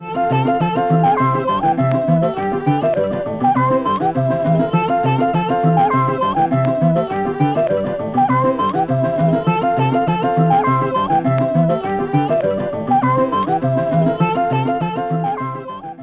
Kategorien: Lustige